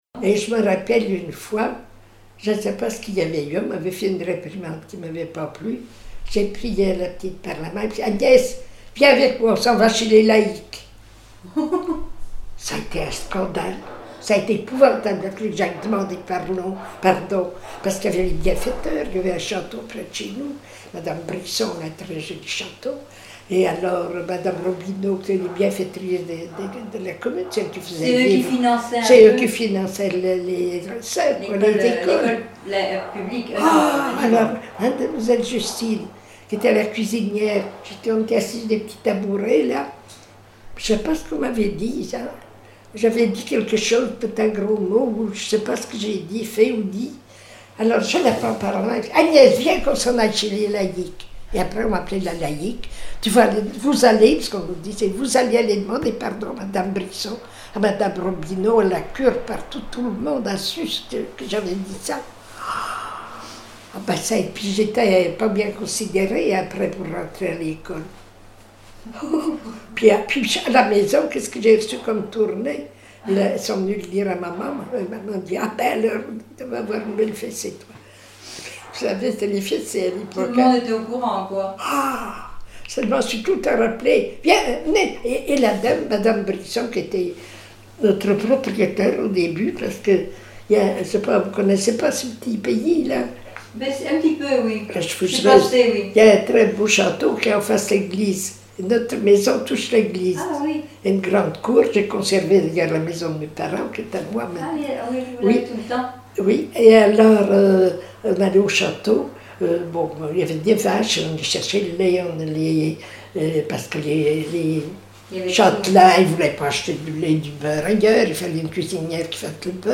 parole, oralité
Témoignage